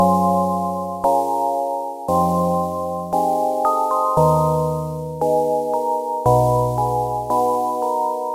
描述：用廉价的木吉他弹了几下。
Tag: 吉他 乱弹